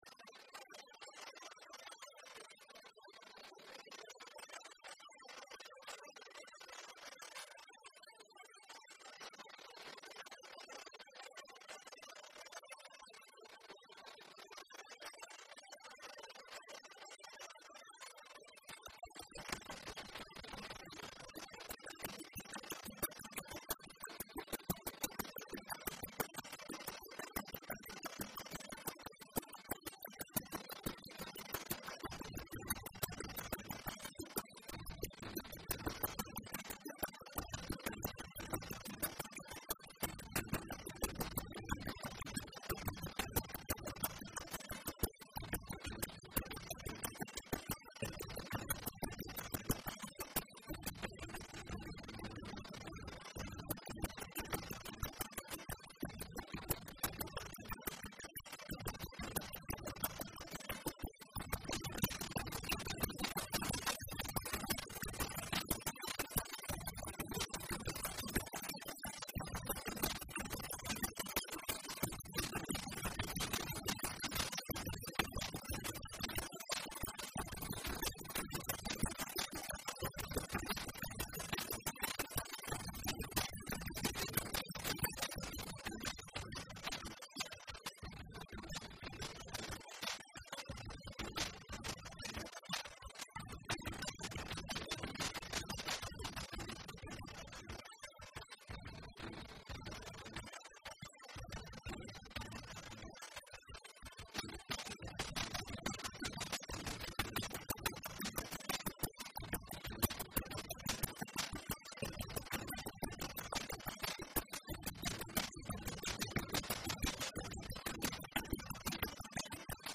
Il ne me reste plus qu'un morceau de Fruity Loops que j'ai appelé "Maya" (j'ai chié avec la basse quasi inaudible...)